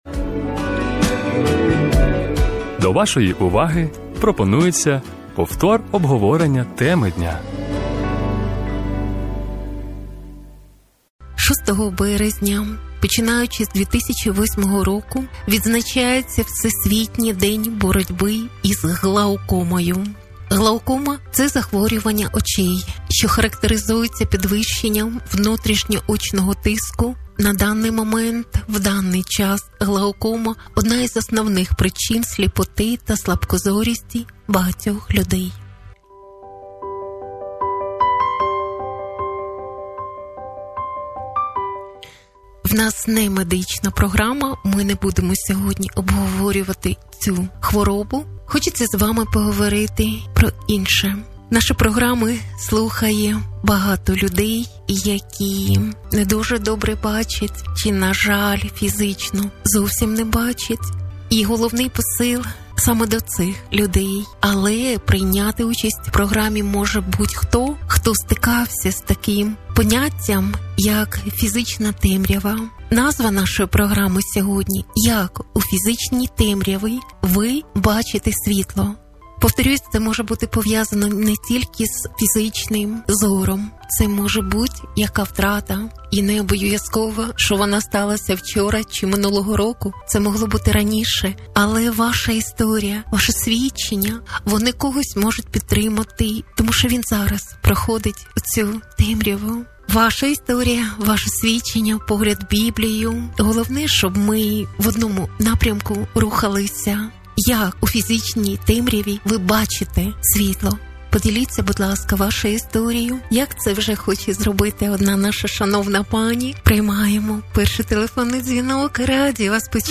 В програмі слухачі розповідають свої історії ЯК В ФІЗИЧНІЙ ТЕМРЯВІ ВОНИ БАЧАТЬ СВІТЛО!